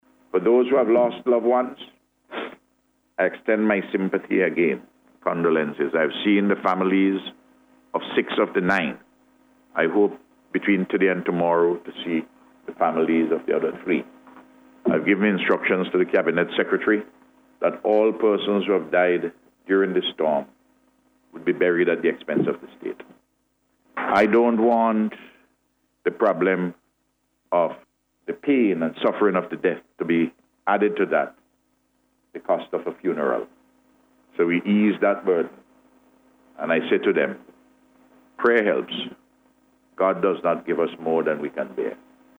This was announced by Prime Minister Dr. Ralph Gonsalves at yesterday’s ceremony at Argyle.